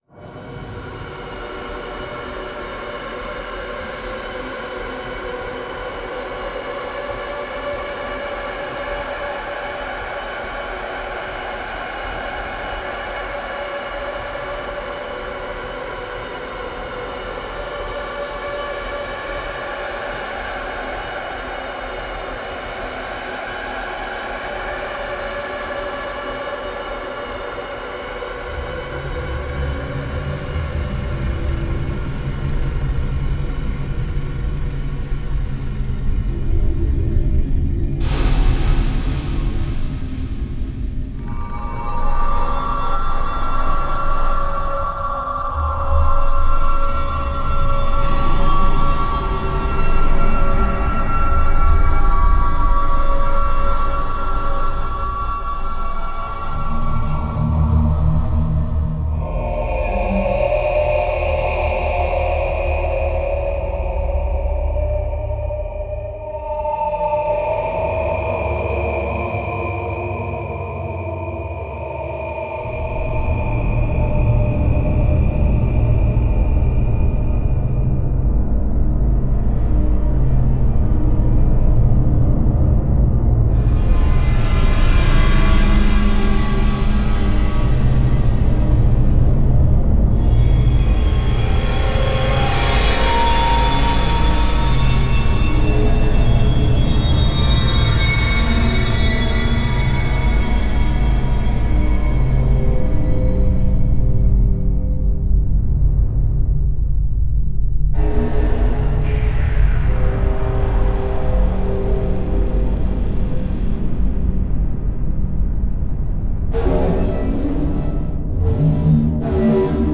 Create a truly eerie atmosphere in your games, movies and more. From ominous ambiences to terrifying impacts.